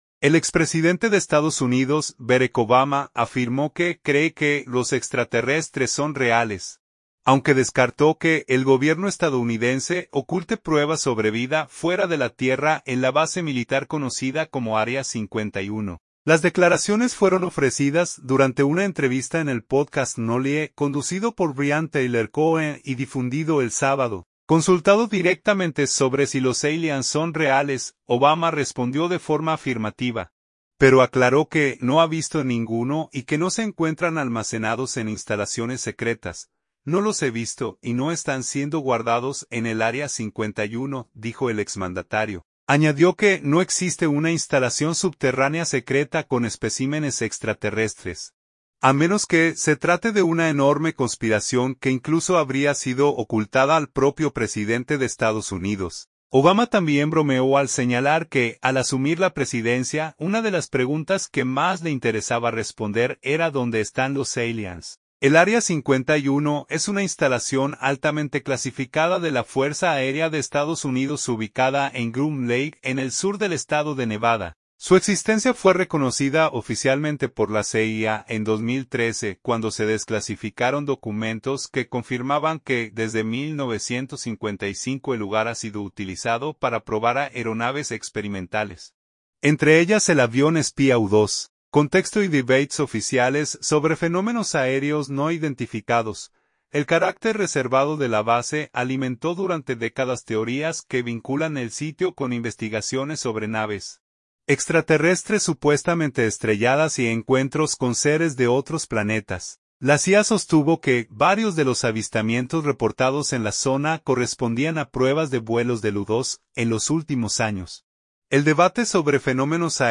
Consultado directamente sobre si los aliens "son reales", Obama respondió de forma afirmativa, pero aclaró que no ha visto ninguno y que no se encuentran almacenados en instalaciones secretas.